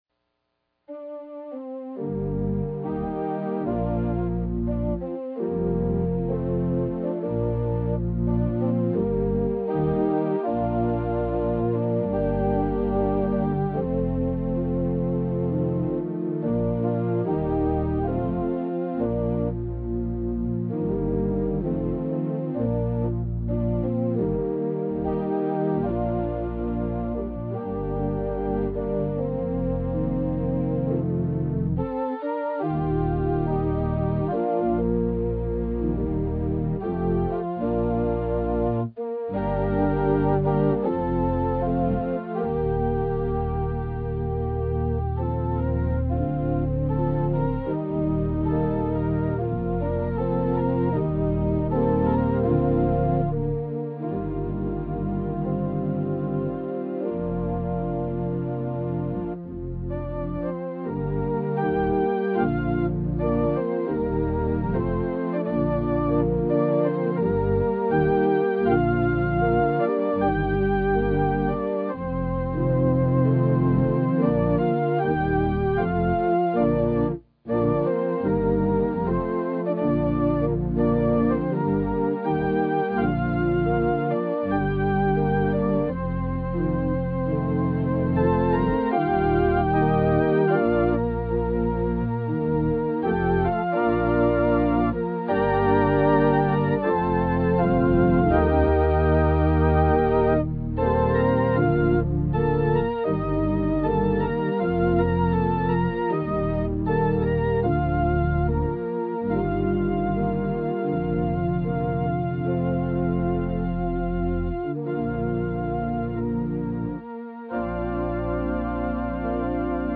Scripture Passage Single Service 2019-03-03